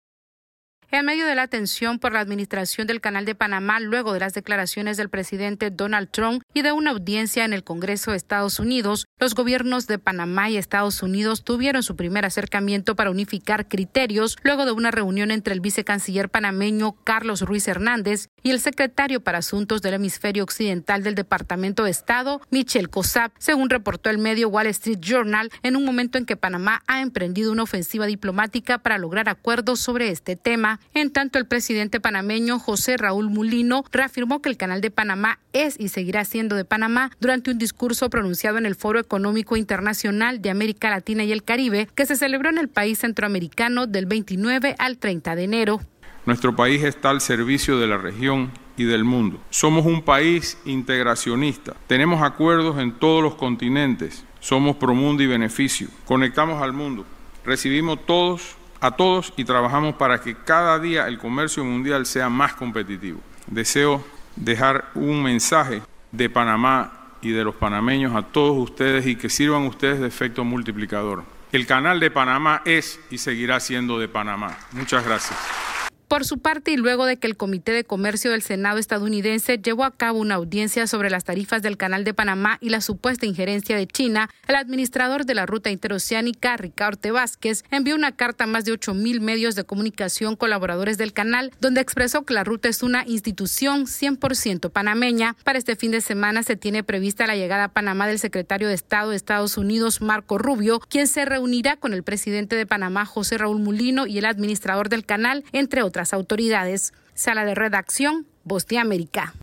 El presidente de Panamá y el administrador del Canal de Panamá reafirmaron que la ruta interoceánica es gestionada 100% por personal panameño y que no cederán ante las presiones de Estados Unidos. Esta es una actualización de nuestra Sala de Redacción.